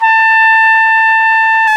Index of /90_sSampleCDs/Roland L-CDX-03 Disk 2/BRS_Piccolo Tpt/BRS_Picc.Tp 1